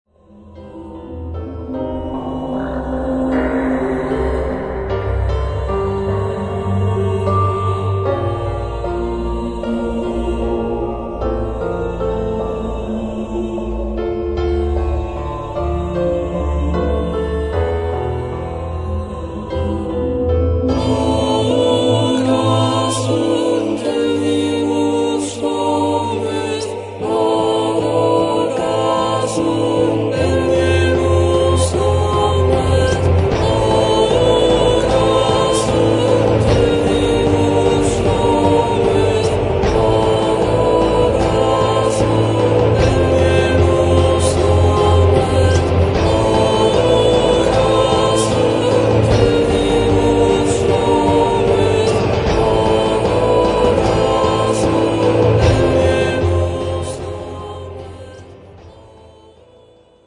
ヴォーカルが若干弱いのが弱点かもしれませんが、それも儚さの演出に役立っているのかも？
cello, vocals
piano, vocals, arrangements